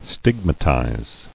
[stig·ma·tize]